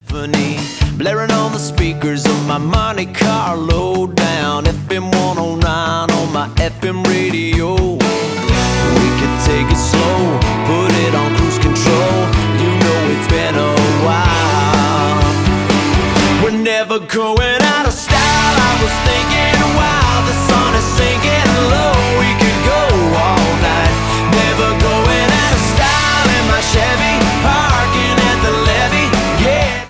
• Country
uptempo country song